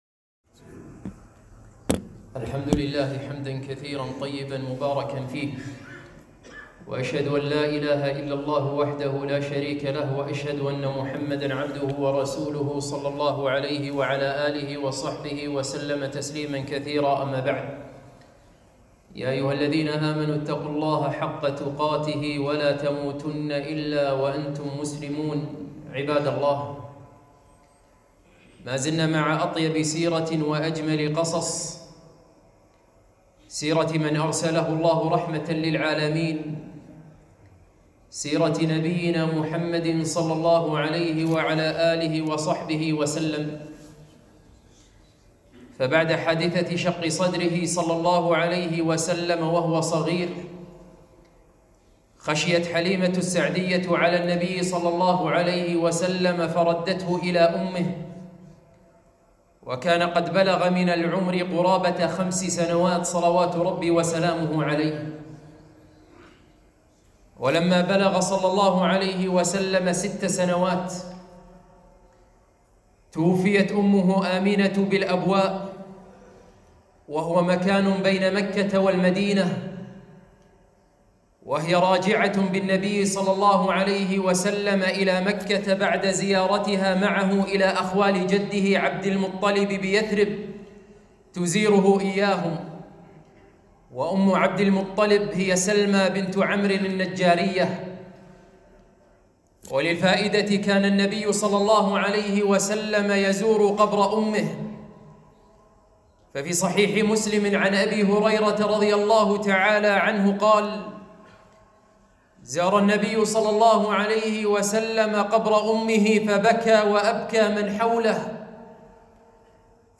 خطبة - موت أم النبي صلى الله عليه وسلم وجده ورحلته إلى الشام وحرب الفجار وحلف الفضول